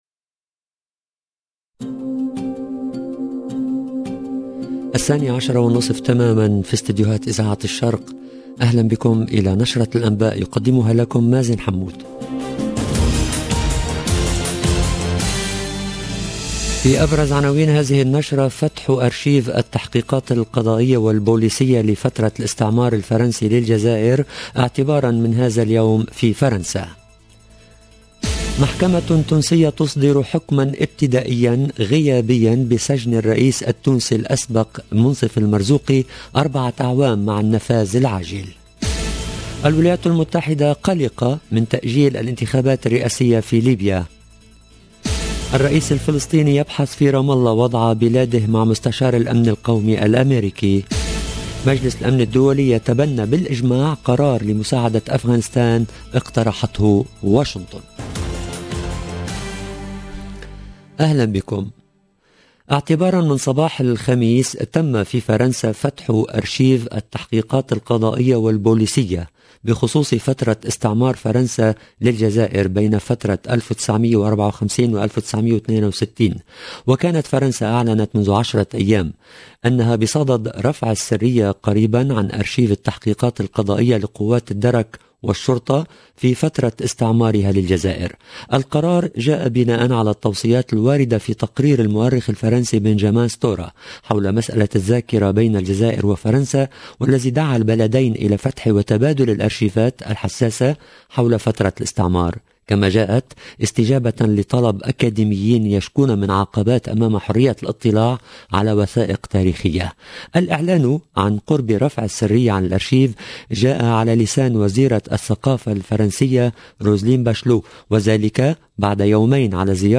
LE JOURNAL DE 12H30 EN LANGUE ARABE DU 23/12/2021